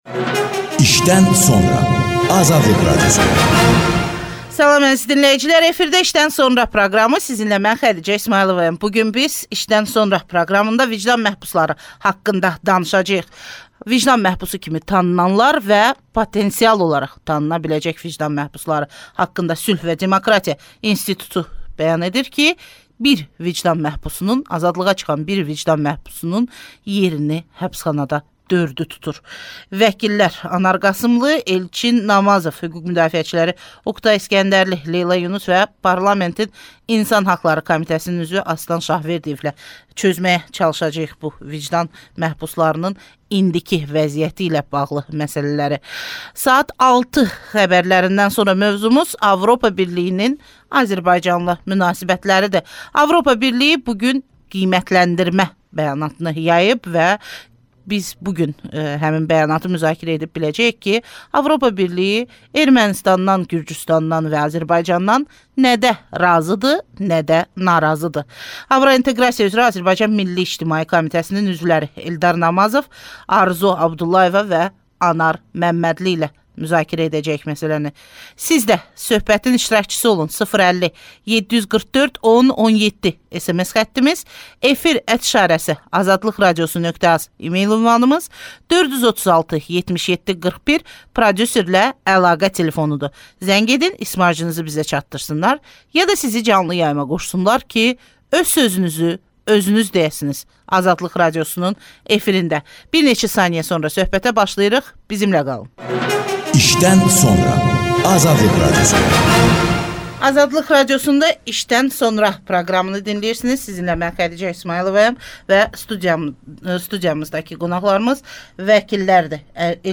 AzadlıqRadiosunun «İşdən sonra» verlişinə müsahibə verən parlamentin İnsan Hüquqları Komitəsinin üzvü Astan Şahverdiyev bu fikirdədir